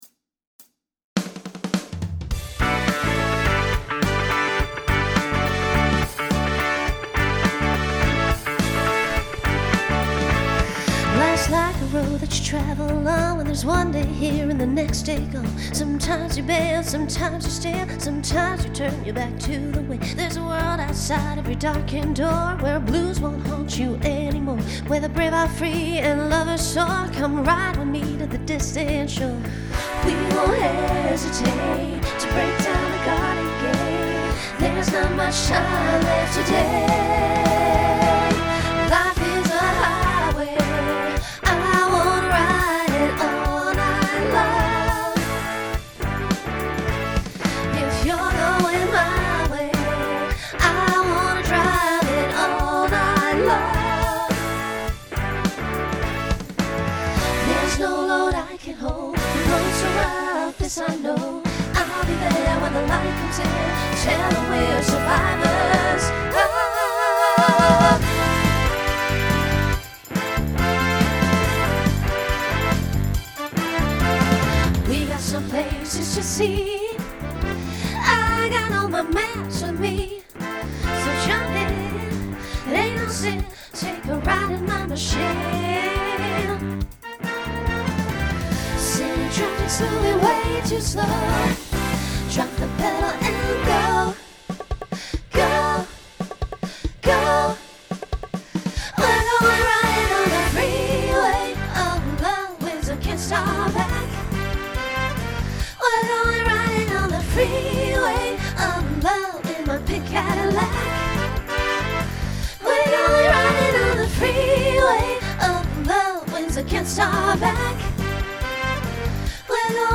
Genre Pop/Dance , Rock
Voicing SSA